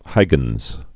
(hīgənz)